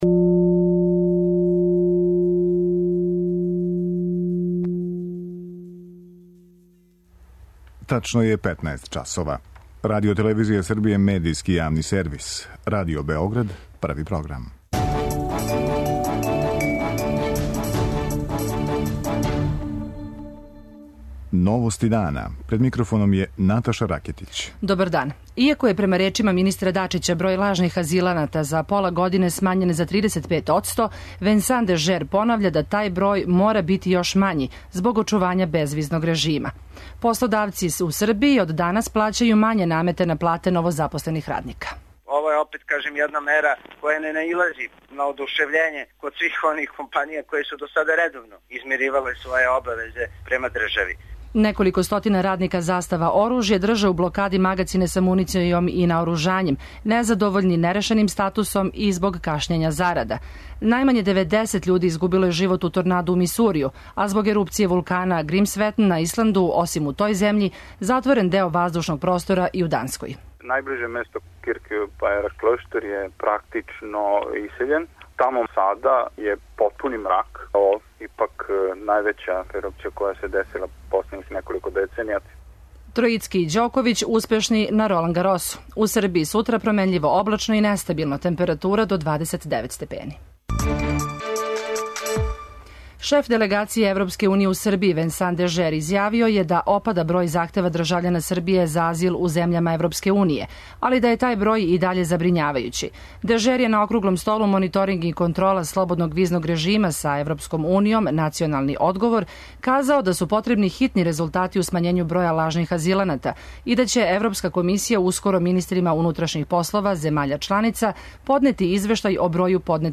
О последицама ерупције овог вулкана за Новости дана говоре стручњаци са Исланда.